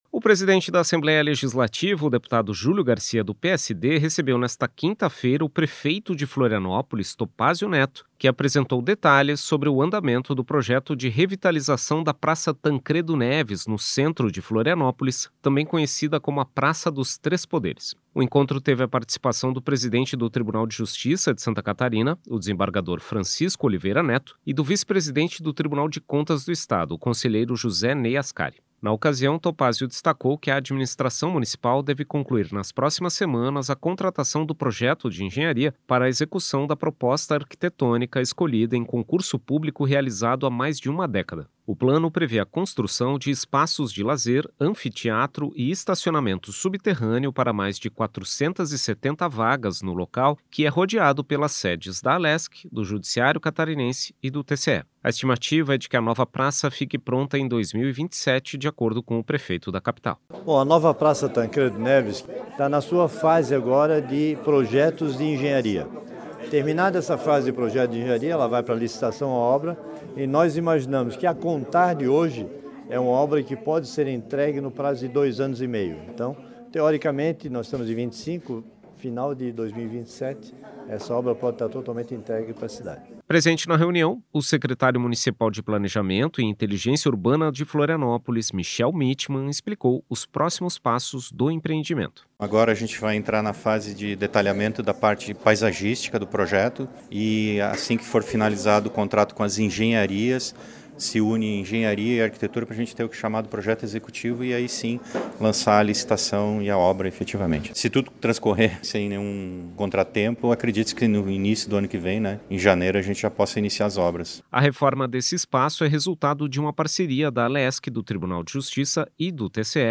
Entrevistas com:
- deputado Julio Garcia (PSD), presidente da Assembleia Legislativa;
- desembargador Francisco Oliveira Neto, presidente do Tribunal de Justiça de Santa Catarina;
- Topázio Neto, prefeito de Florianópolis;